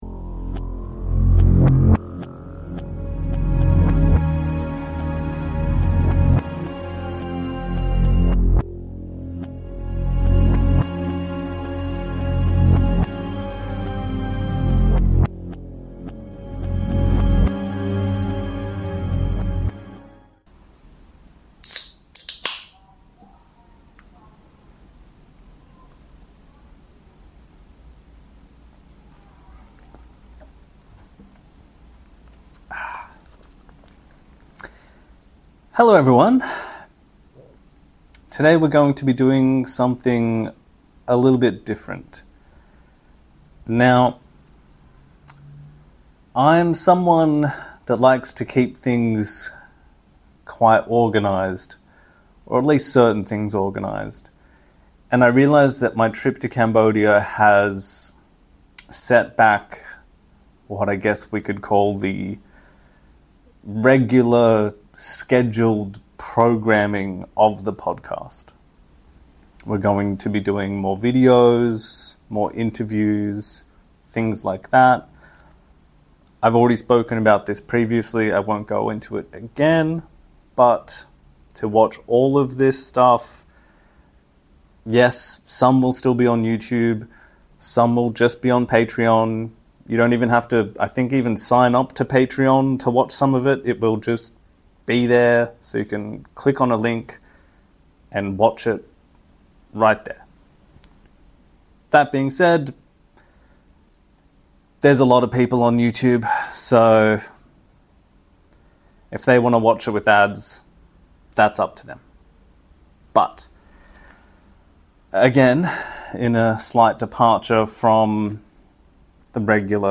In this bonus episode, I sit down to go through and explain an interview I recently conducted with an ex-Khmer Rouge doctor, who we will refer to as ‘uncle’.